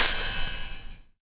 Index of /90_sSampleCDs/USB Soundscan vol.20 - Fresh Disco House I [AKAI] 1CD/Partition D/01-HH OPEN